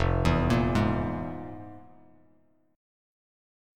FmM7bb5 chord